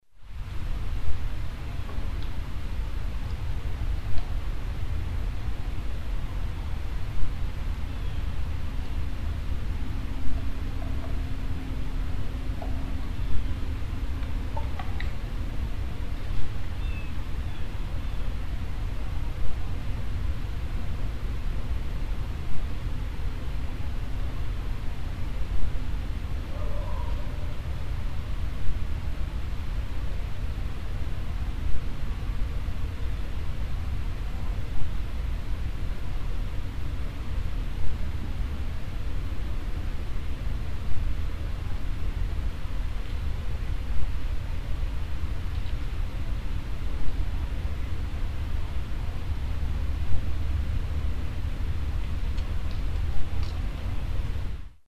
From October 11, 2009 in the Adirondack Park, Warren Co, NY. The soft melodic random knocks starting at 0:11, that sounded like they came from the forest on the near side of the pond, not far from the recorder.Â  At 0:26, a more distant (whawhoooo) vocalization from the far side of the pond. Although the vocalization sounds canine, and there were coyotes present in the area… the sound is not quite like any coyote vocalization I’ve heard, to present. Equipment used was a Zoom H2 recorder set out in a tree for an overnight recording.
ovrnght_10-11_clip_whooh-knocks.mp3